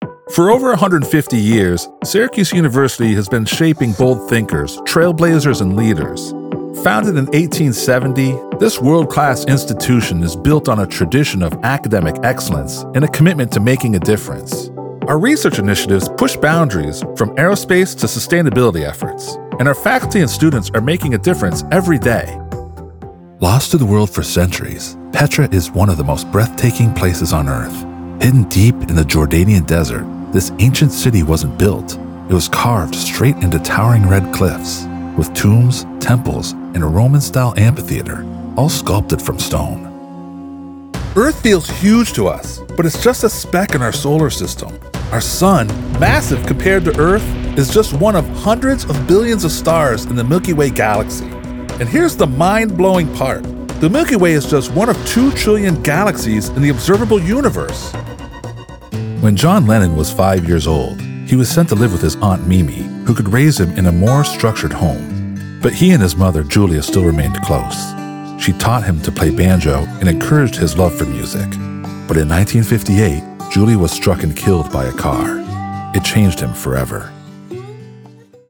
Commercial and Narration Demos
My voice has been described as deep yet dynamic, commanding yet gentle and soothing, allowing me to adapt to a wide range of styles and genres.
I work from my home studio, using a Lewitt LCT 240 Pro microphone, through a PreSonus Audiobox USB96 interface, using Windows 11.